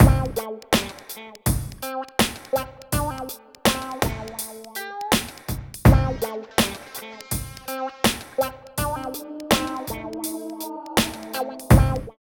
134 LOOP  -R.wav